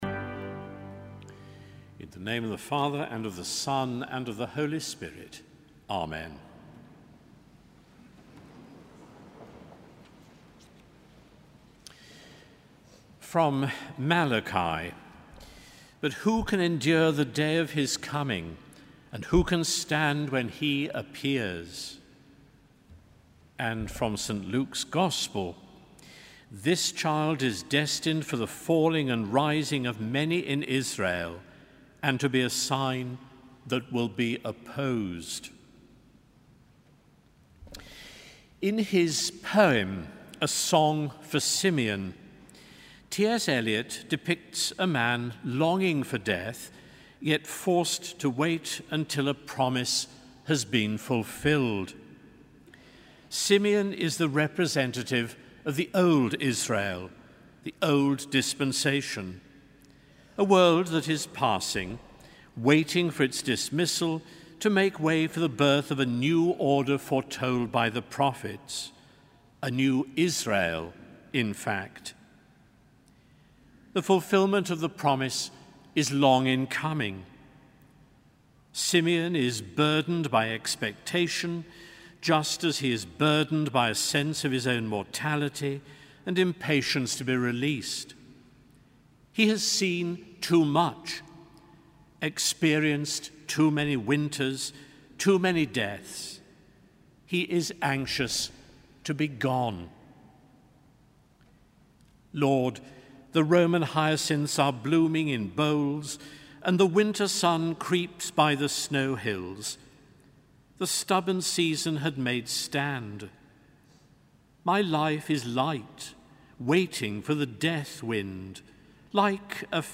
Sermon: Eucharist 2 February 2014